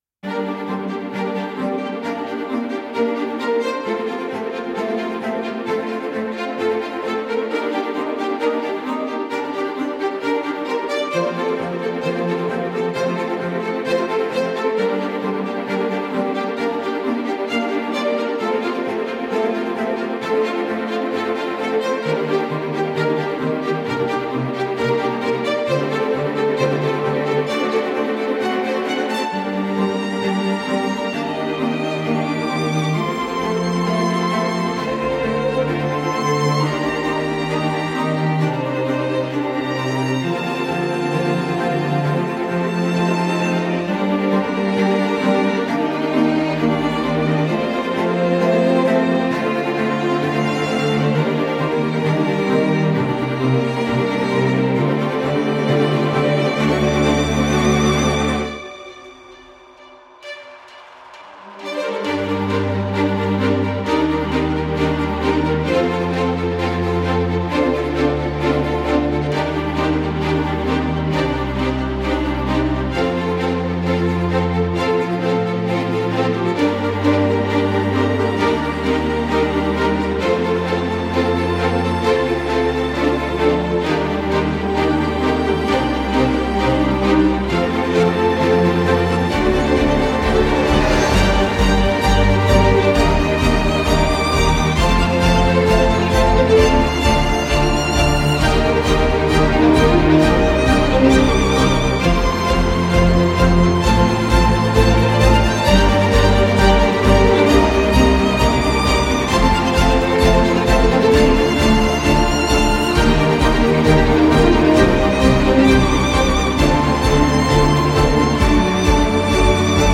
موسیقی بی کلام